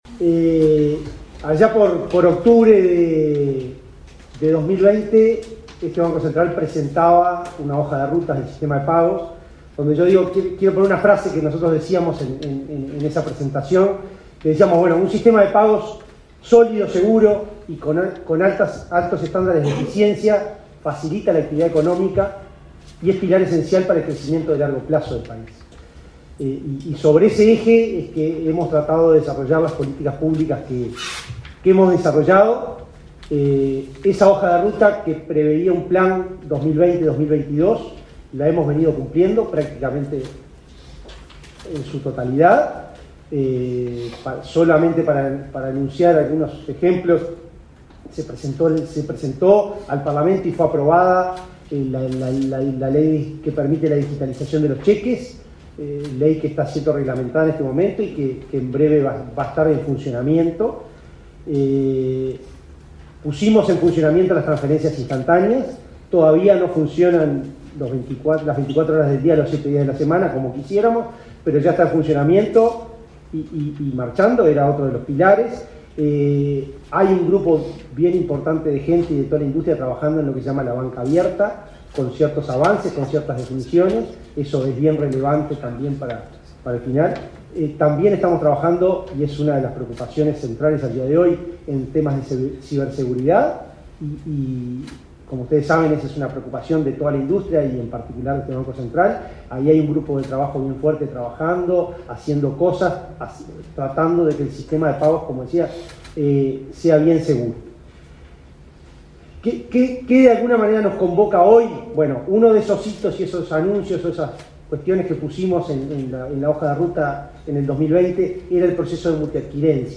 Declaraciones del presidente del BCU
El presidente del Banco Central del Uruguay (BCU), Diego Labat, brindó una conferencia de prensa a fin de informar sobre cambios para los comercios en